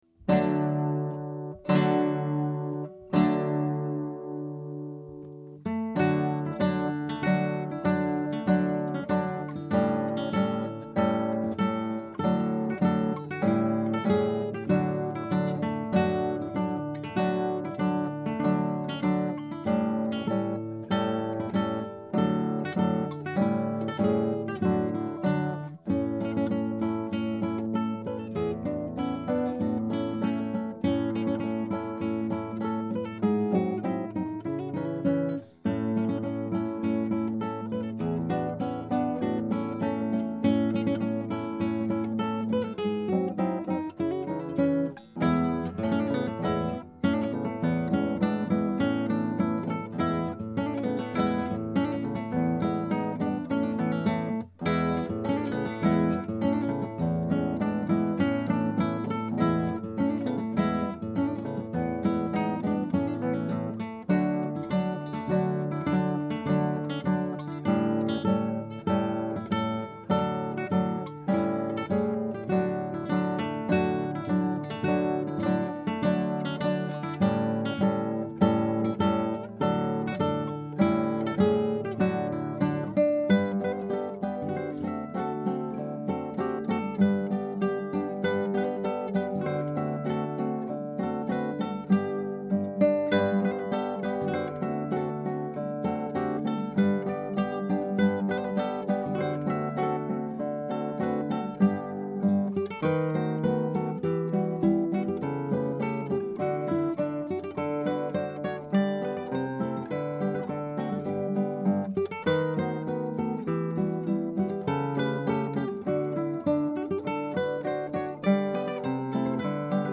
Scraps from the Operas arranged for Two Guitars
Scrap 1: Allegro.